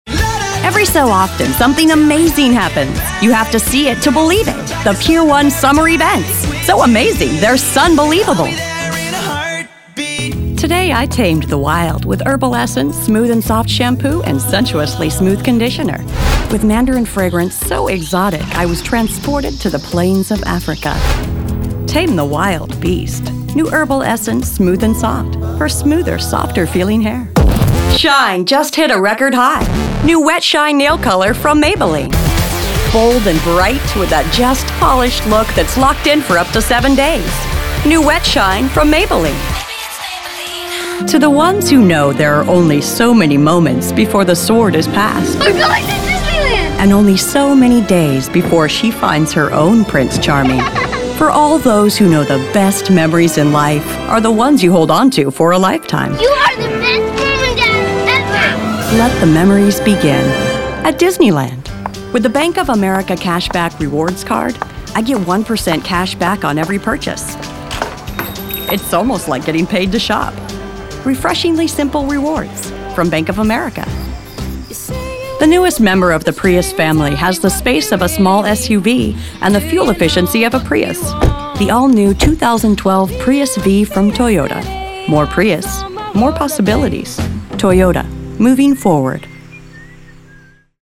englisch (us)
Kein Dialekt
Sprechprobe: Werbung (Muttersprache):